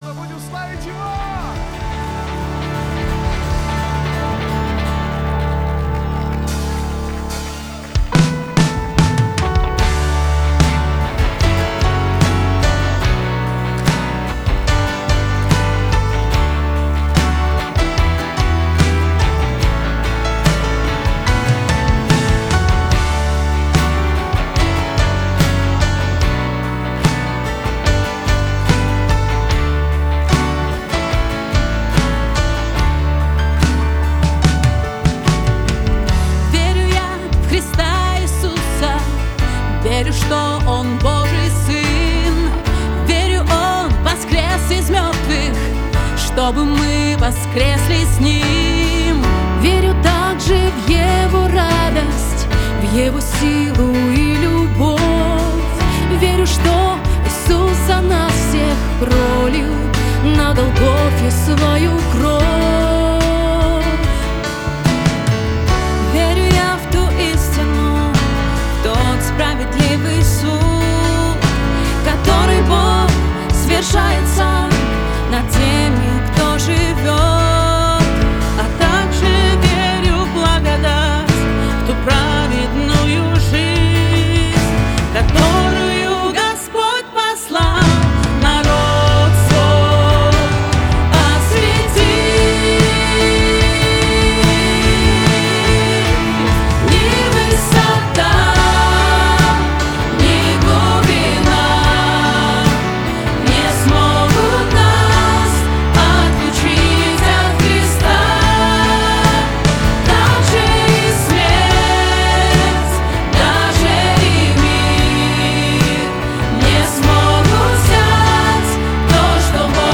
487 просмотров 60 прослушиваний 1 скачиваний BPM: 73